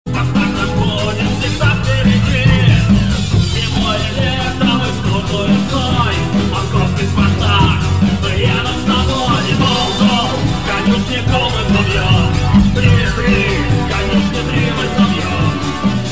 гитара
футбольные
noise rock
шумные